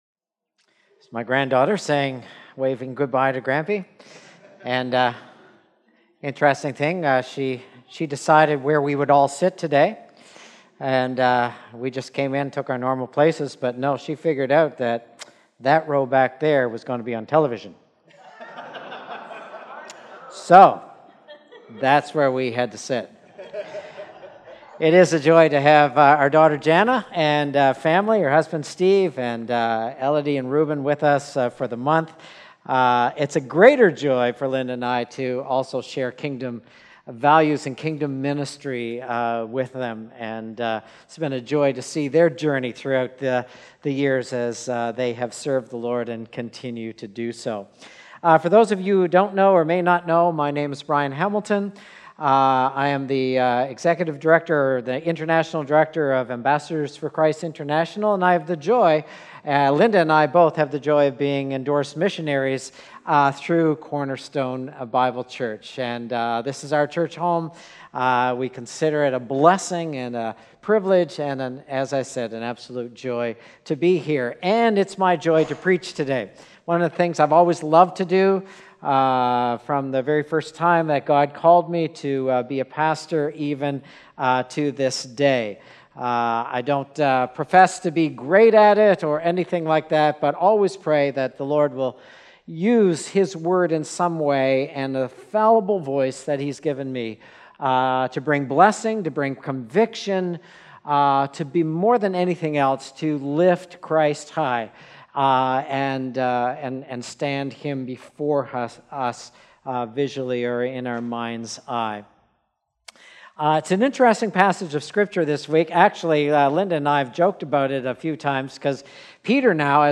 Sermon Detail
August_4th_Sermon_Audio.mp3